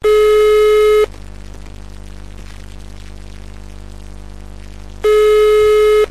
Kategorien: Telefon